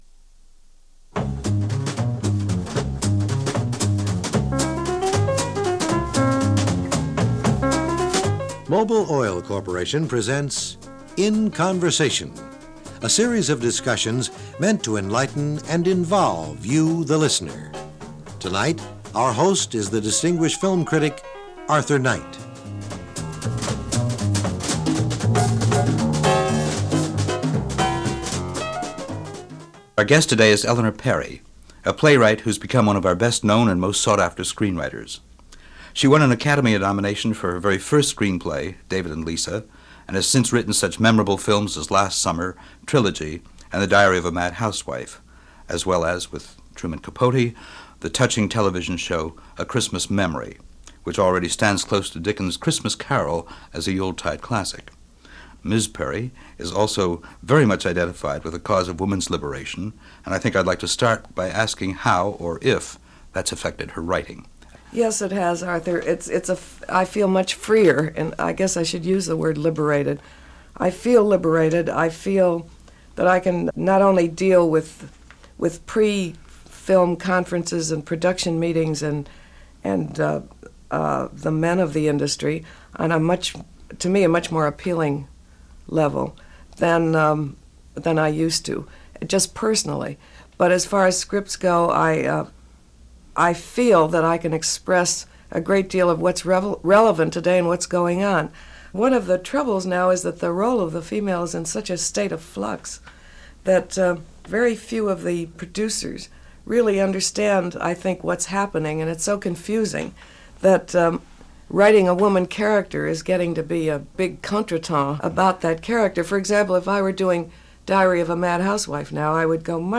Perry, sitting down for this 1974 interview with Film critic and historian Arthur Knight pulls no punches over what was considered (in 1974) the sad state of affairs with regards to how Women were portrayed in film at the time – how the Film Industry was very much the boys club and how Feminism was still struggling with stereotypes, only a few years after its initial appearance.